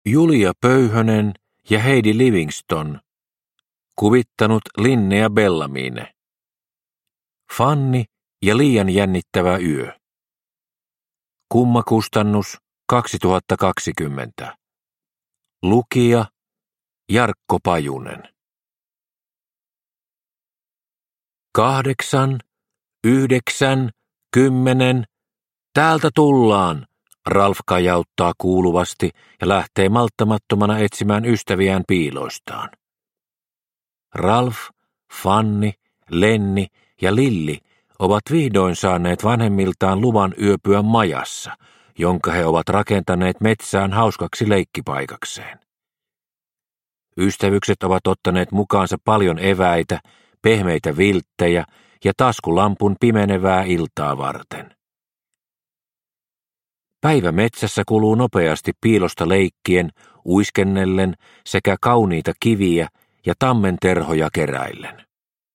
Fanni ja liian jännittävä yö – Ljudbok – Laddas ner